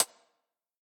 Index of /musicradar/ultimate-hihat-samples/Hits/ElectroHat D
UHH_ElectroHatD_Hit-32.wav